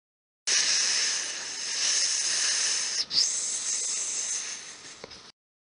Звуки утечки
На этой странице собраны различные звуки утечек: от капающей воды до шипящего газа.